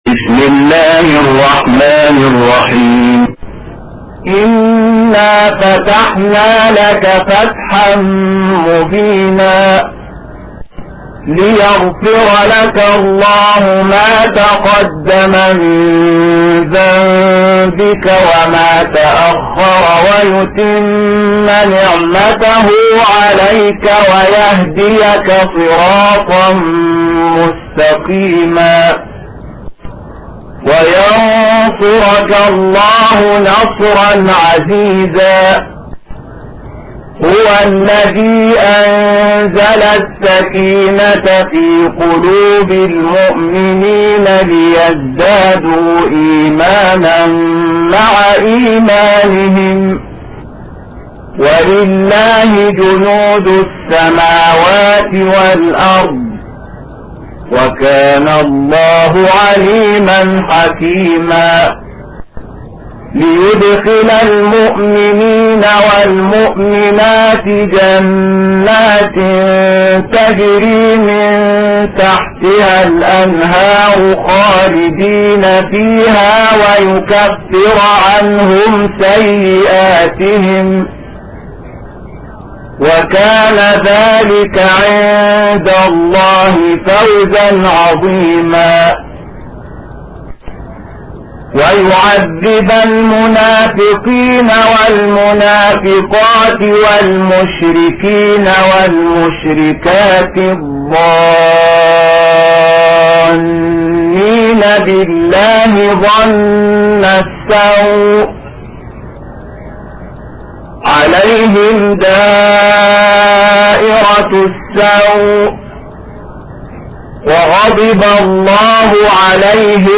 تحميل : 48. سورة الفتح / القارئ شحات محمد انور / القرآن الكريم / موقع يا حسين